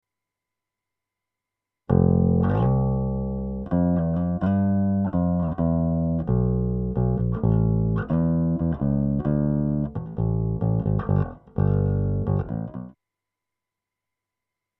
で、以前録音したベースをCubaseで再生してVG-88を経由で録音する事にする。
ベース本体のEQもいぢらずに前PUの音をライン直録り。エフェクトは一切無し。
テンポ130で6小節のイントロの最後をフェードアウトさせて前後に1小節ずつ空白を挟んだ。
VGのDRIVER SETTINGはTYPEがGK-2A、SCALEはST。
10mmが明るく30mmが暗い印象は変わらず。